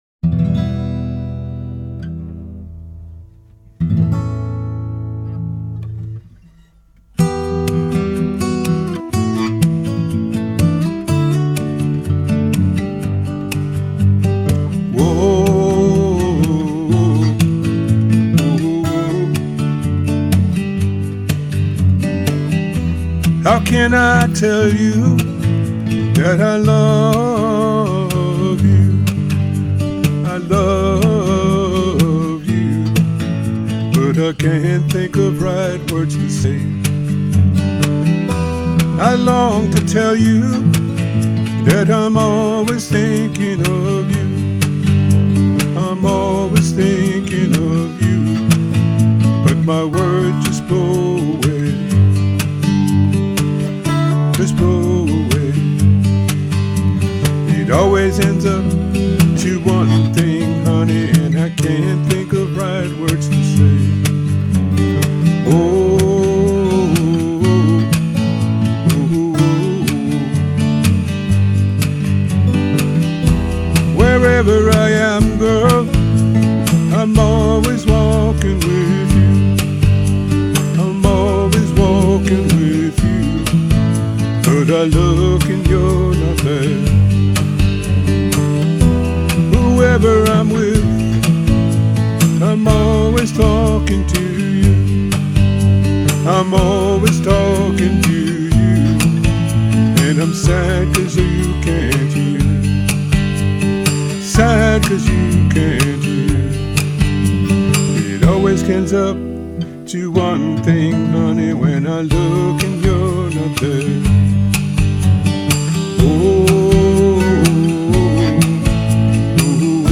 digital instrumentation.